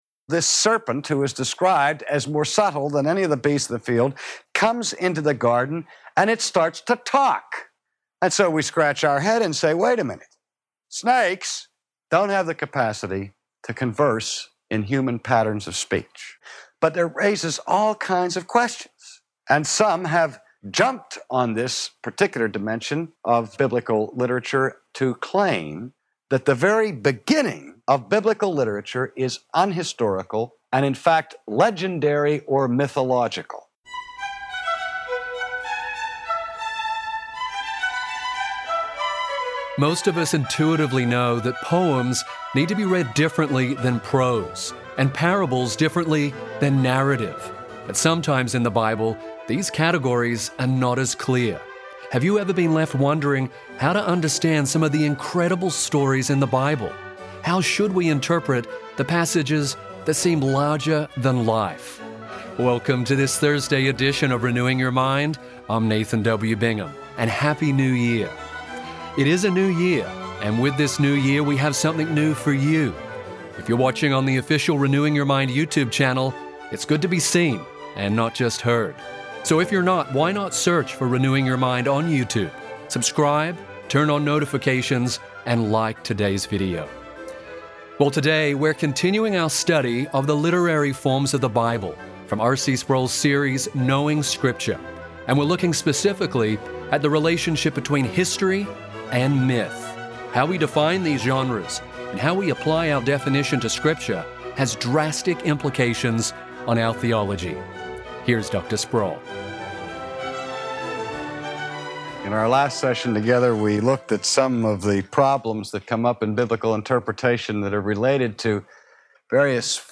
Since the Bible sometimes uses symbolic language, how can we know that Jesus' resurrection literally took place? Today, R.C. Sproul explains how a clear study of Scripture's literary forms upholds the Bible's historical reliability.